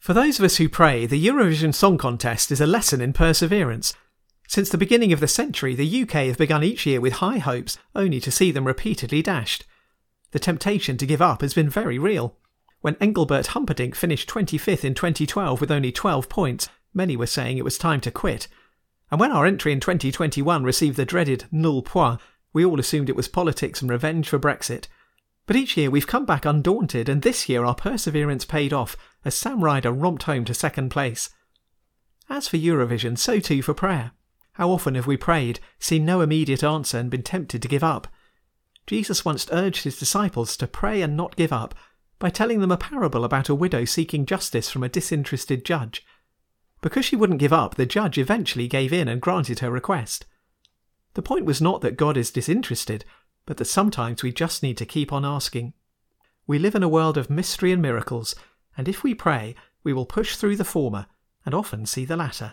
The recordings are posted weekly and accessed by the SHORT THOUGHT button at the top of each web page.